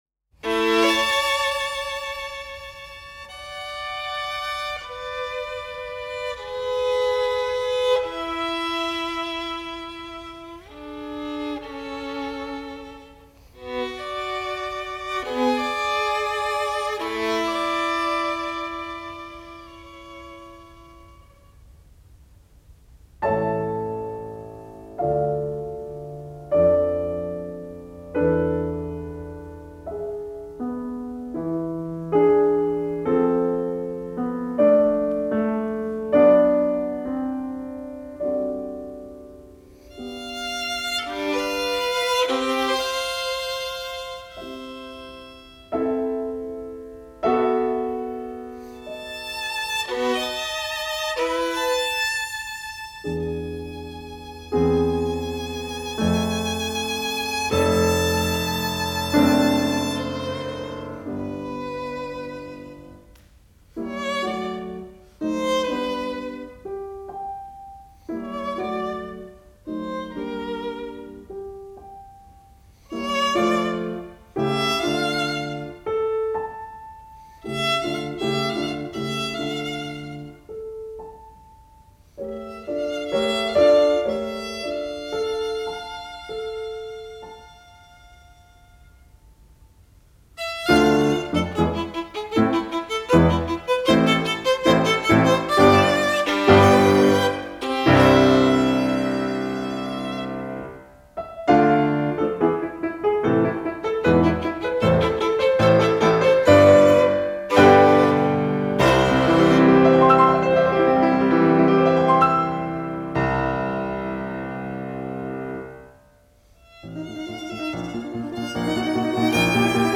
More history this weekend by way of Radio Cologne in this 1956 performance of the Kreutzer sonata for Violin and Piano featuring Hans Richter-Haaser, piano and Henryk Szeryng, violin – it was recorded on June 25, 1956 in the studios of Kölner Rundfunk.